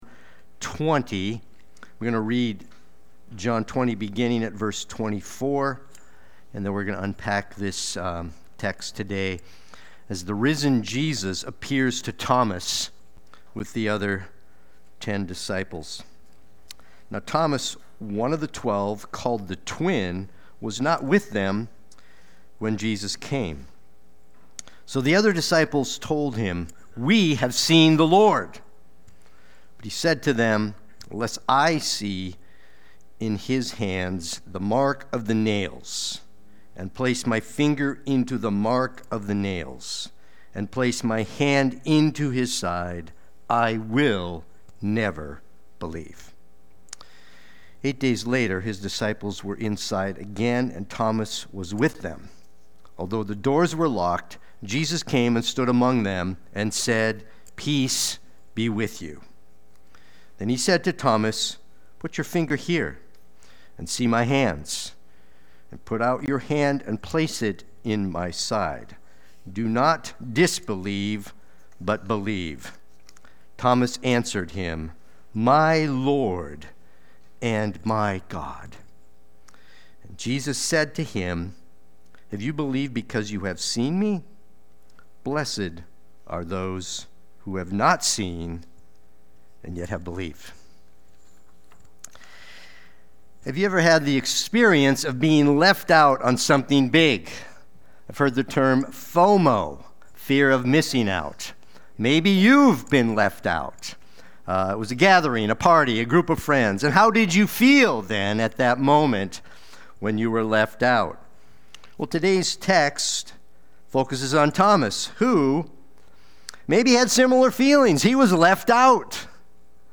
Watch the replay or listen to the sermon.
Sunday-Worship-main-33025.mp3